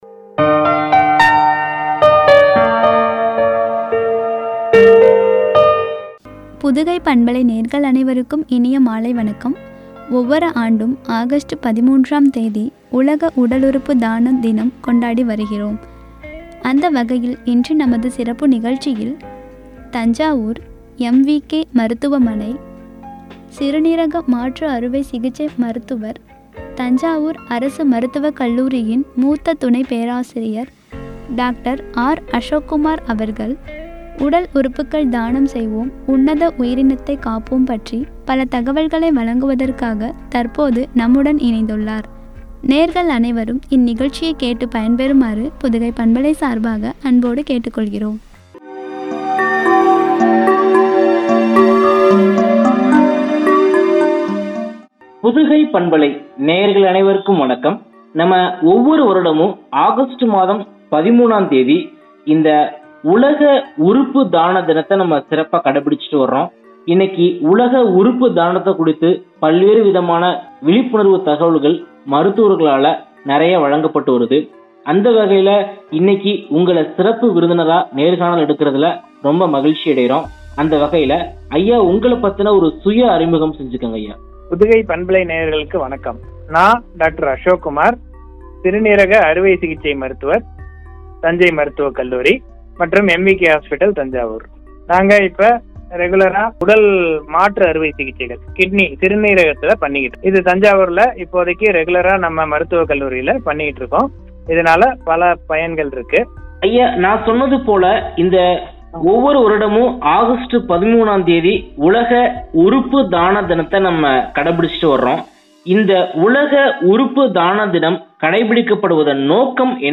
எனும் தலைப்பில் வழங்கிய உரையாடல்.